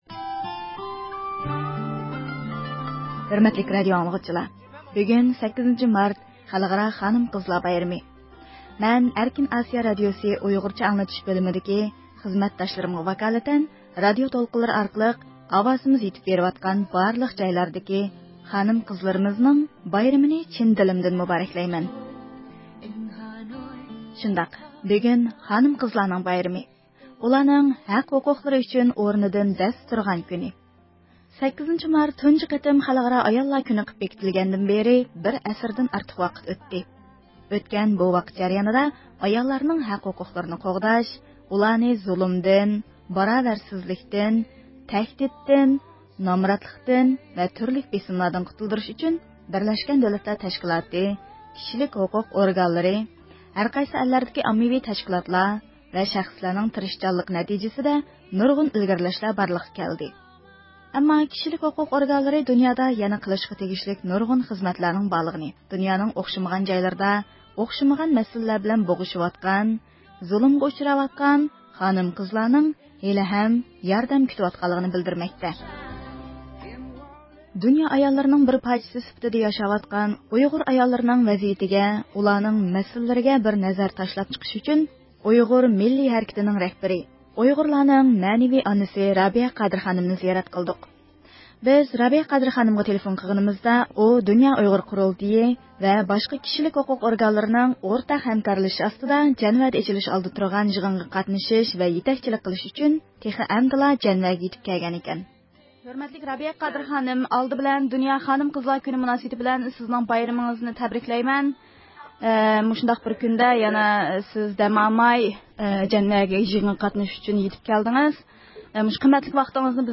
ھۆرمەتلىك رادىئو ئاڭلىغۇچىلار، بۈگۈن 8 – مارت خەلقئارا خانىم – قىزلار بايرىمى.
بىز رابىيە قادىر خانىمغا تېلېفون قىلغىنىمىزدا ئۇ دۇنيا ئۇيغۇر قۇرۇلتىيى ۋە باشقا كىشىلىك ھوقۇق ئورگانلىرىنىڭ ئورتاق ھەمكارلىشىشى ئاستىدا جەنۋەدە ئېچىلىش ئالدىدا تۇرغان يىغىنغا قاتنىشىش ۋە يېتەكچىلىك قىلىش ئۈچۈن تېخى ئەمدىلا جەنۋەگە يېتىپ كەلگەن ئىكەن.